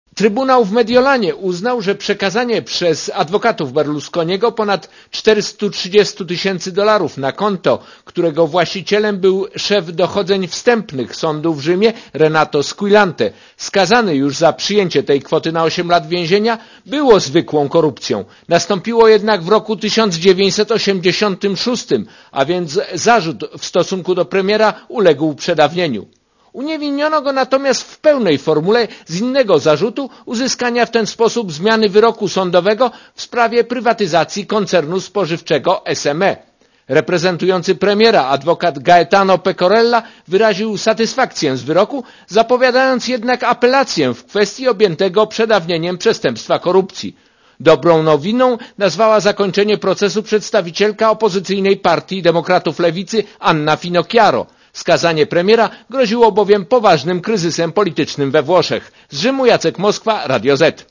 Korespondencja z Włoch